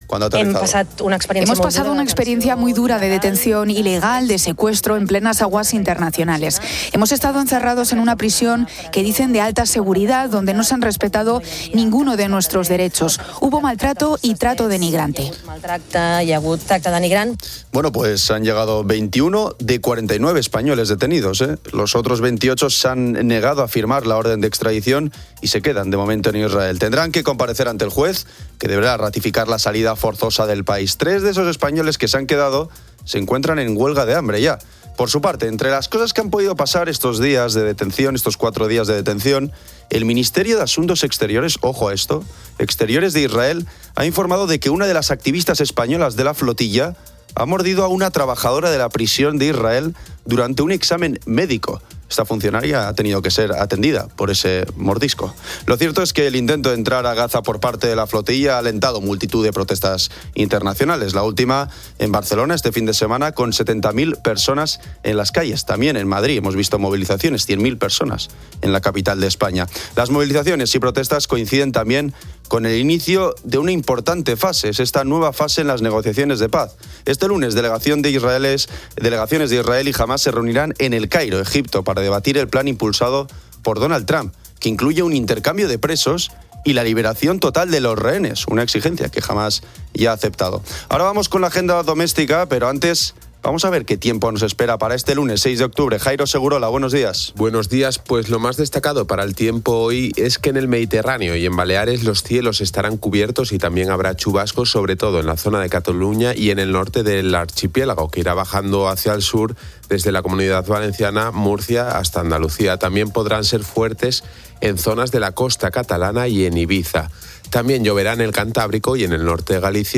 Oyentes de COPE de diversas profesiones envían saludos.